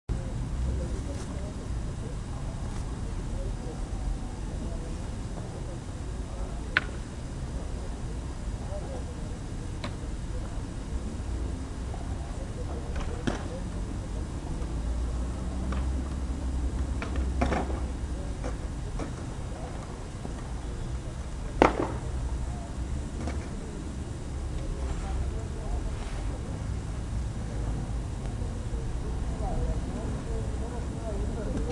滑板运动 " 滑冰
描述：滑板在混凝土平面上的声音。
标签： 下降 传球 混凝土 公园 市2005 滑板 大气 城市
声道立体声